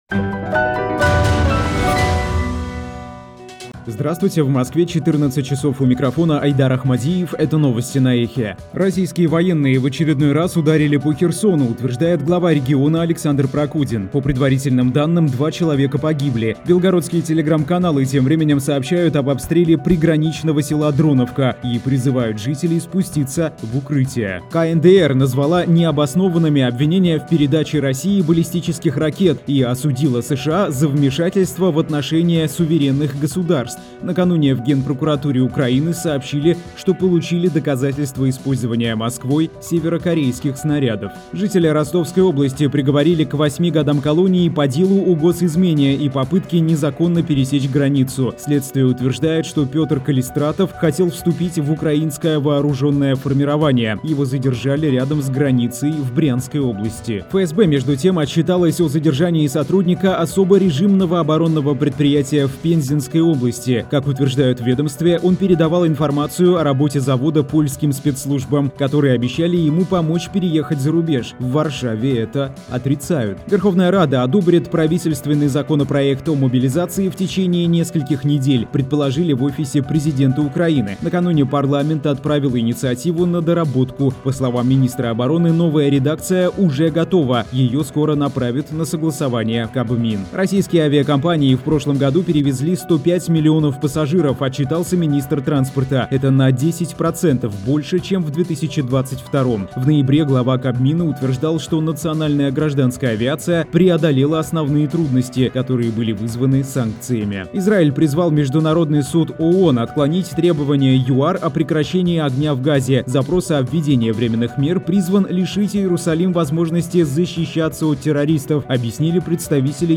Слушайте свежий выпуск новостей «Эха»
Новости 14:00